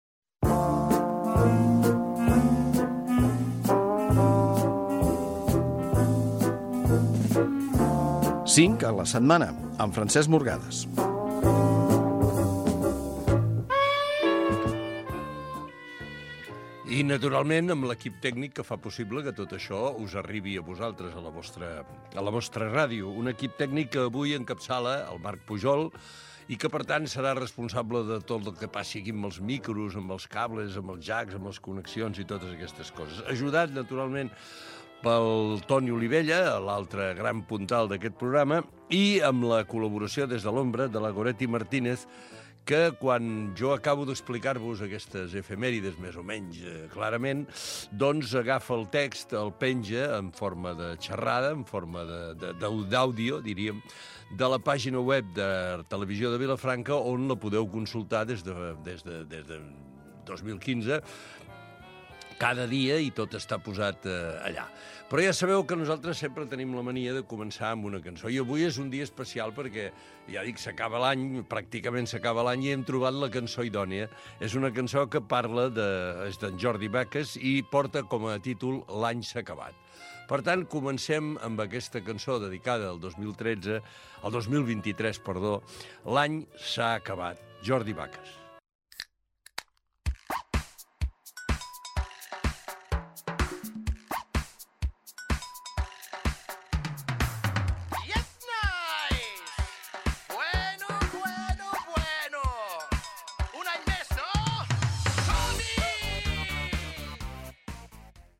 Careta del programa, equip, tema musical
Entreteniment